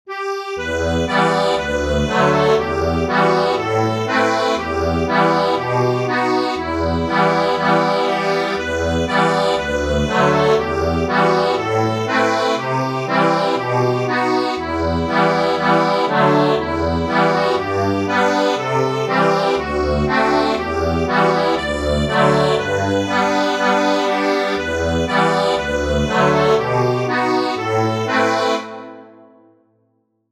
Instrument:  Accordion
A lovely traditional carol